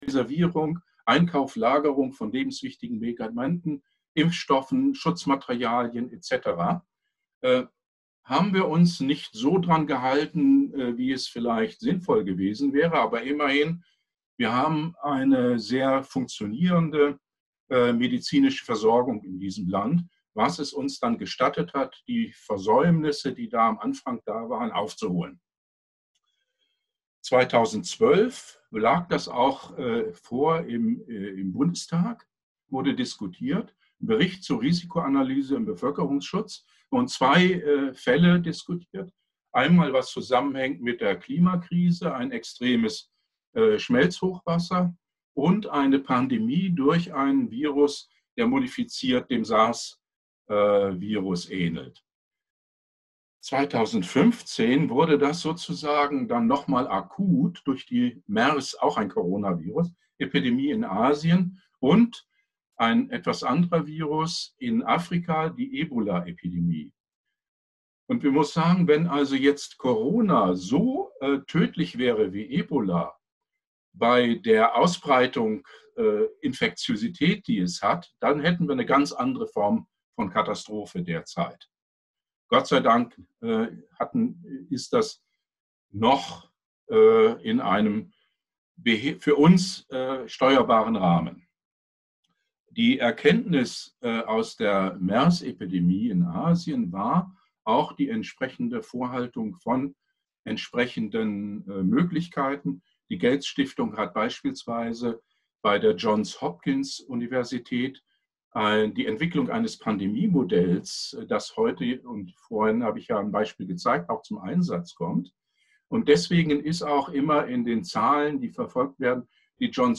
Online-Seminar: Lehren aus der Corona Krise für eine vorsorgende Klimaschutzpolitik in Brandenburg
Leider fehlen die ersten ca. 10 min der Aufnahme, wir bitten dies zu entschuldigen.